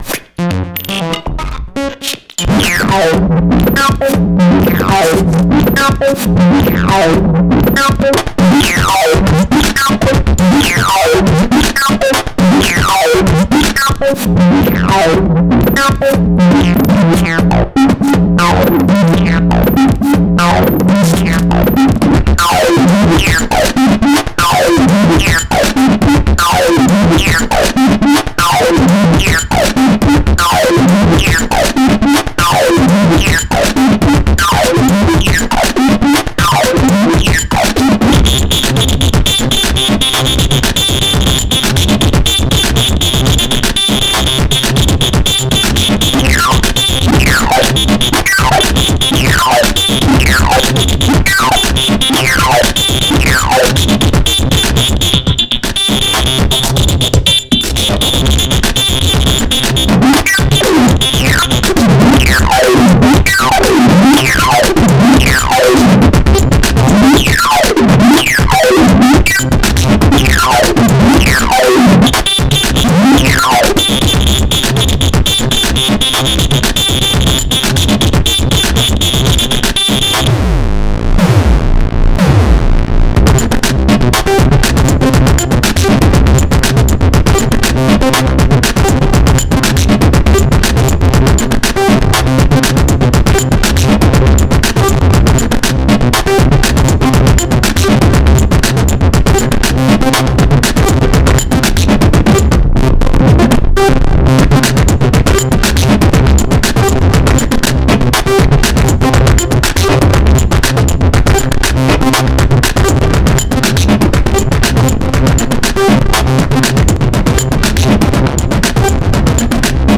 This is a sound made with my music equipment.
asid techno
Synthesizer